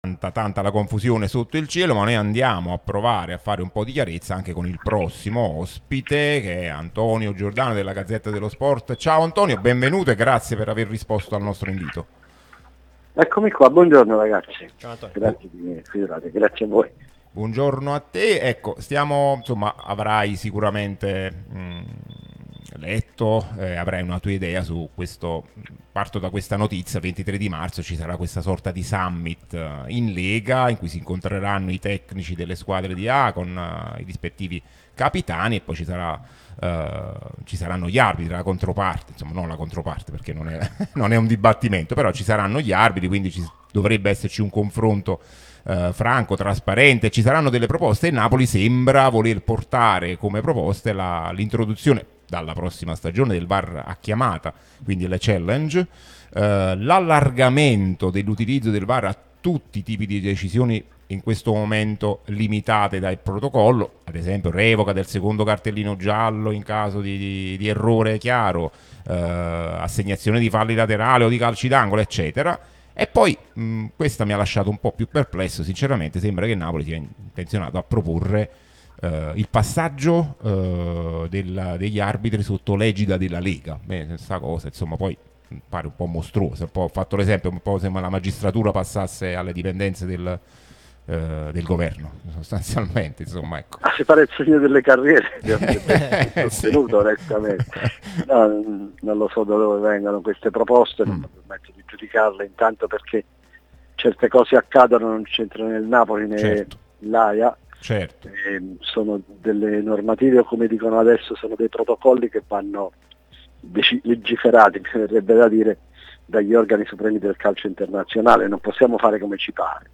Radio Tutto Napoli